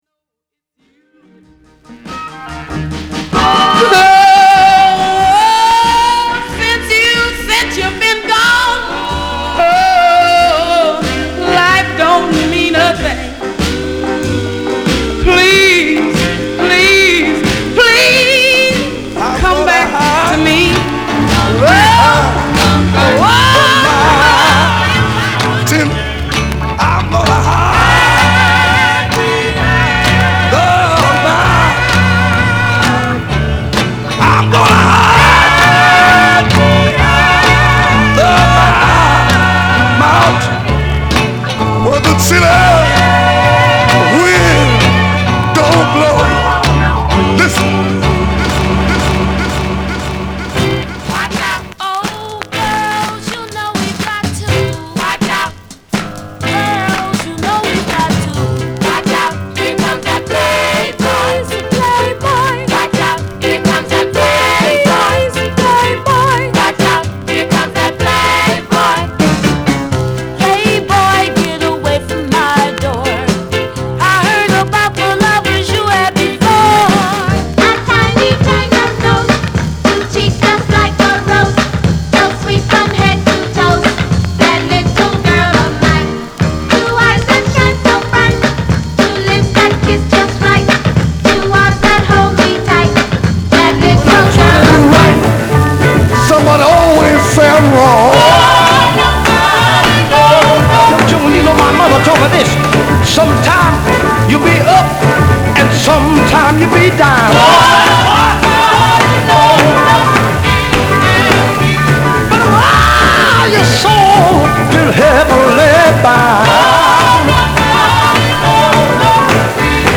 R&B、ソウル
/盤質/両面やや傷あり/US PRESS